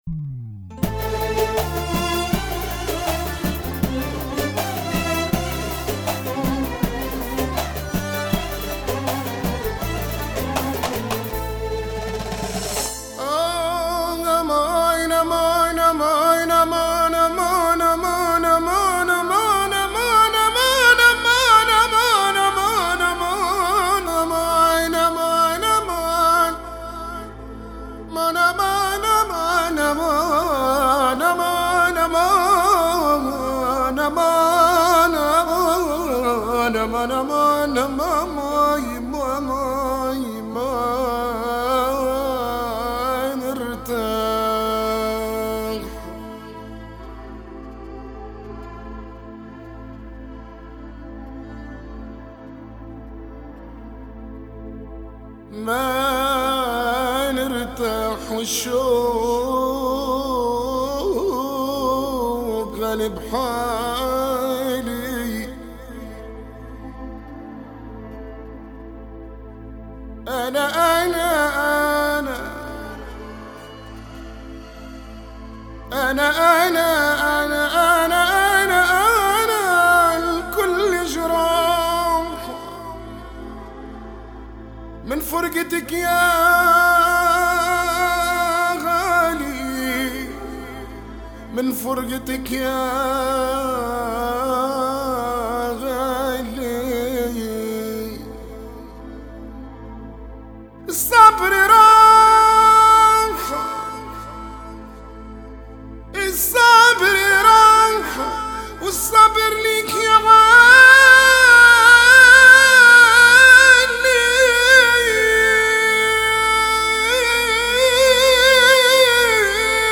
Bienvenue au site des amateurs de Mezoued Tunisien
chanson